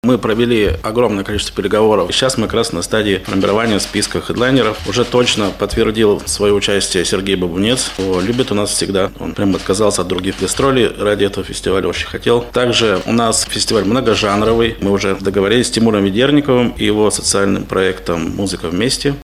на пресс-конференции «ТАСС-Урал»